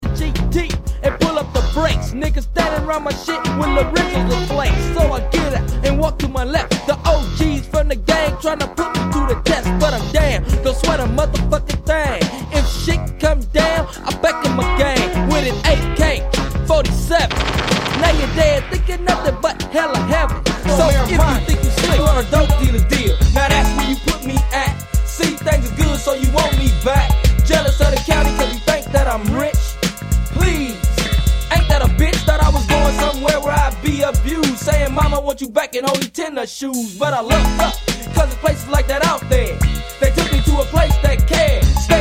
Cali g-funk/gangsta rap